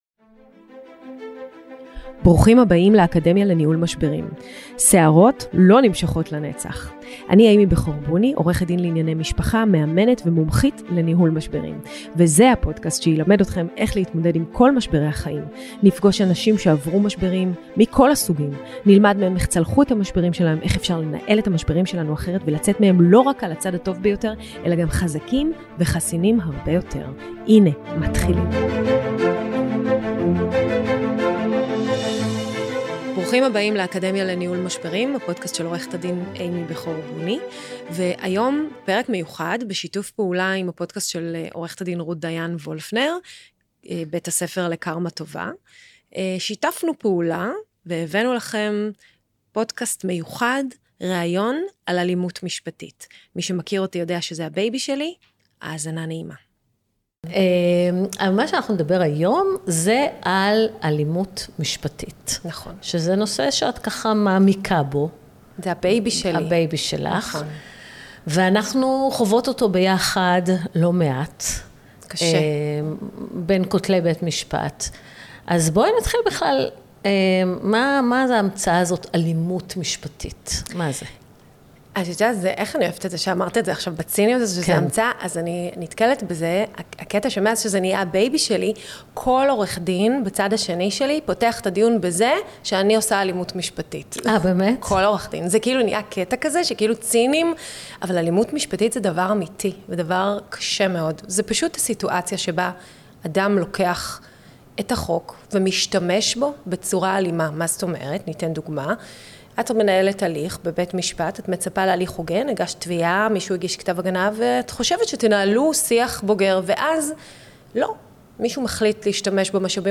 ראיון על אלימות משפטית
הפרק הזה הוקלט לפודקאסט בית הספר לקארמה טובה.